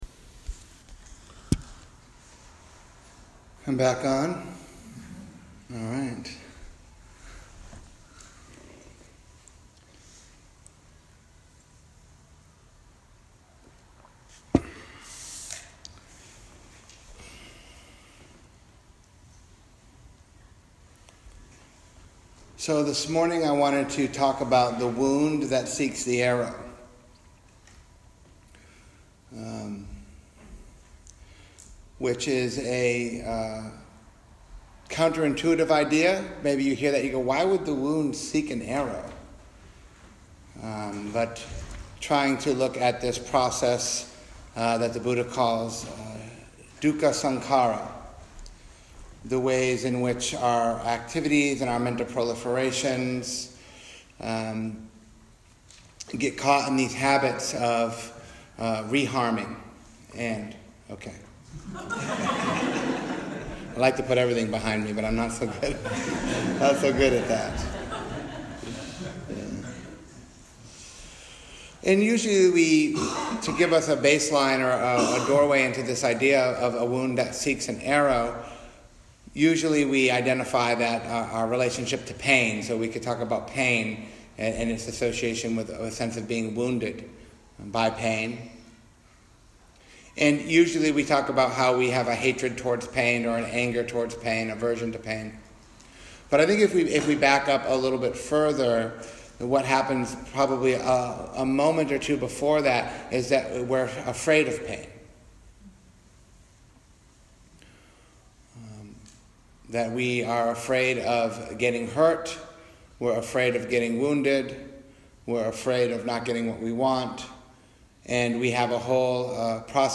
A talk on embracing wounded-ness. Emphasizing that the hearts strength lies in its weakness. A call for vulnerability.